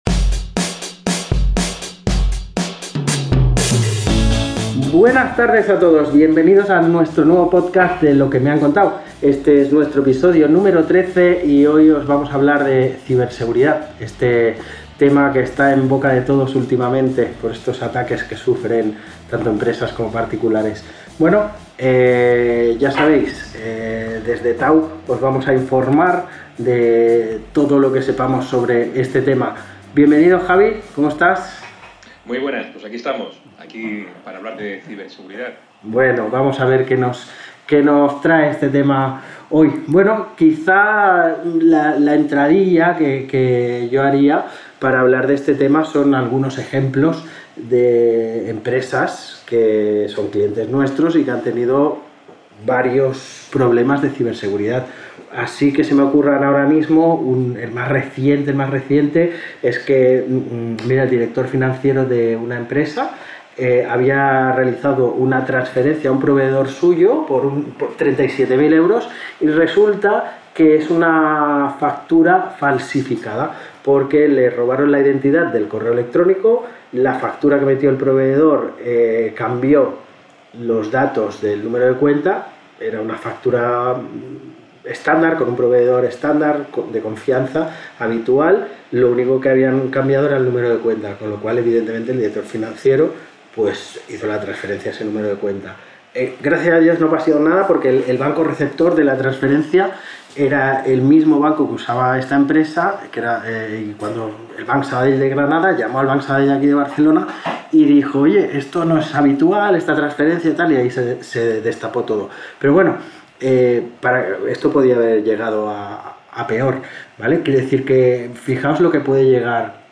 ¡No te pierdas esta interesante conversación sobre ciberseguridad en el último episodio de «Lo que me han contau»!